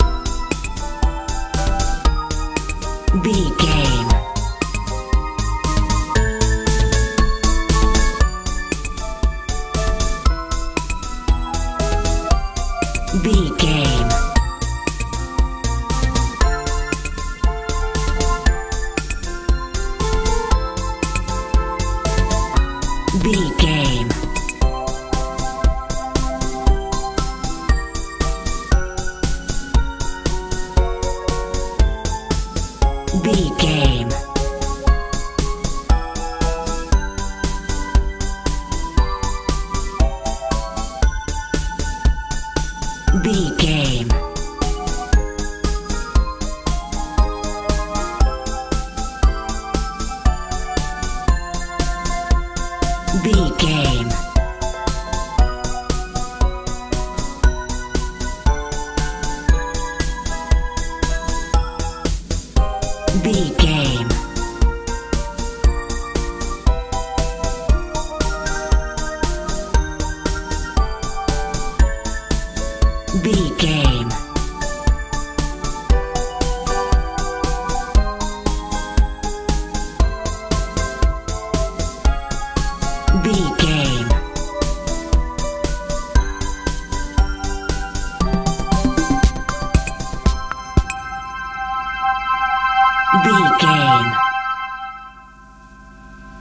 omodern dance feel
Ionian/Major
joyful
hopeful
bass guitar
drums
synthesiser
80s
90s
soft
strange